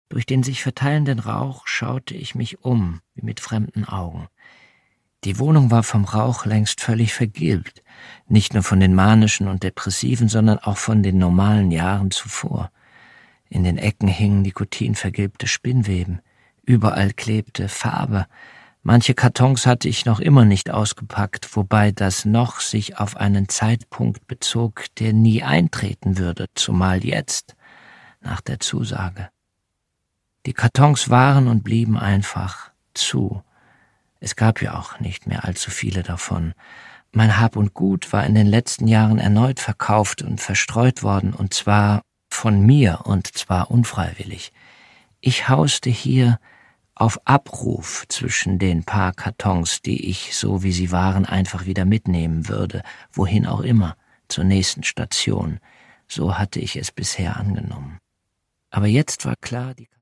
Produkttyp: Hörbuch-Download
Gelesen von: Jens Harzer